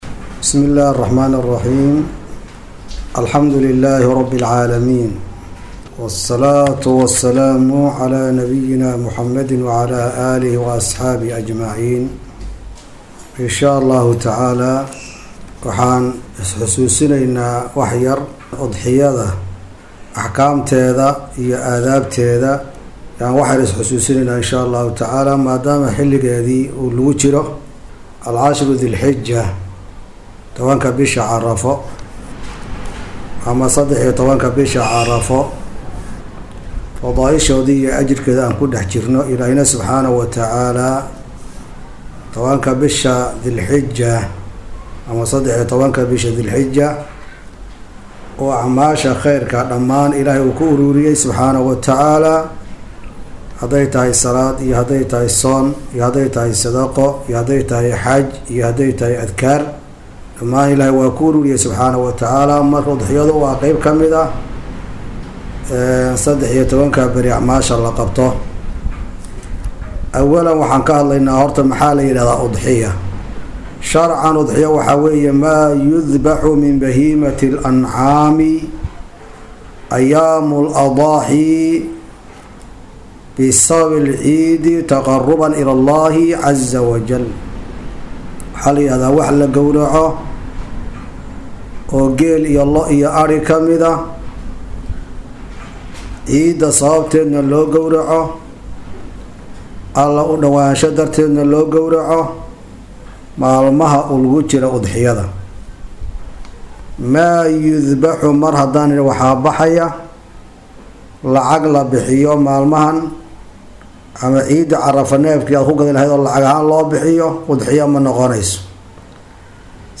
Iyadoo Maalinta Bari ah oo ah Sabti ay tahay maalinta kowaad ee Ciidul Adxaa waxa uu webseydka Calamada idiin soo gudbinayaa muxaadaro ku aadan aadaabta ciida iyo Udxiyada.